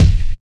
Rich Mids Kick Single Hit E Key 43.wav
Royality free bass drum tuned to the E note. Loudest frequency: 270Hz
rich-mids-kick-single-hit-e-key-43-86G.mp3